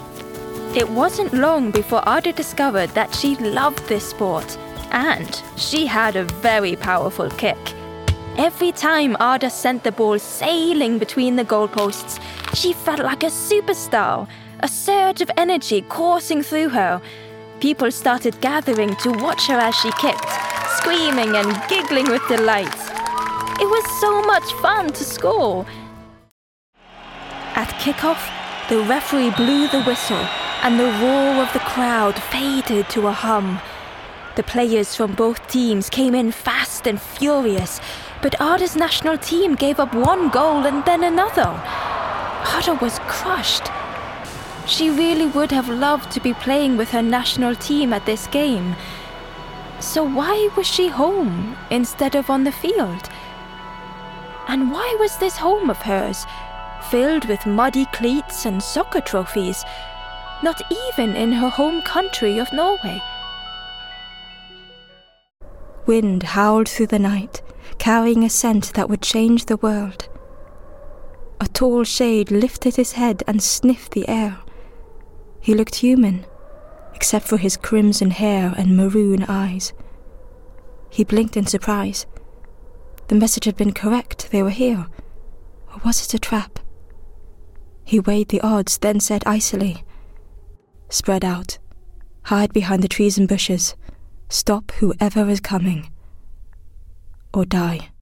Audiobook Reel - Accented English
I'm a highly experienced Scandinavian voice artist with a fully equipped home studio in London.